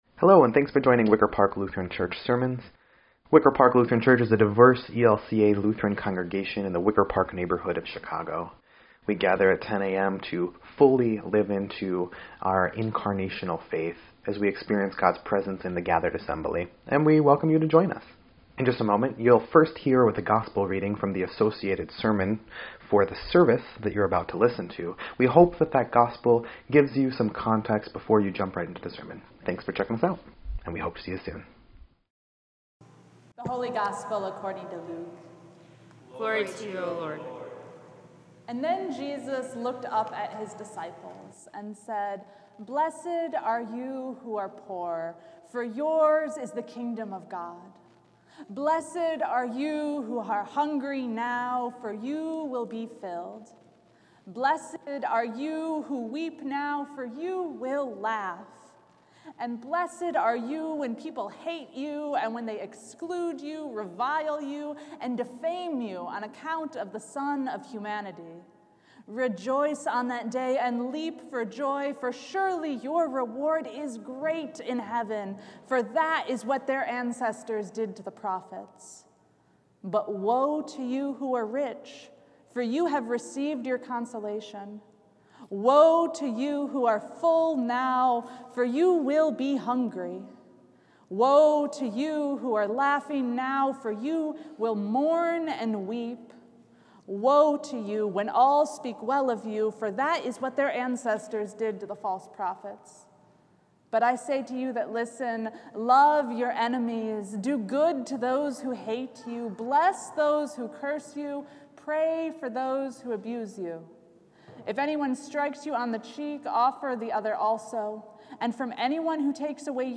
11.6.22-Sermon_EDIT.mp3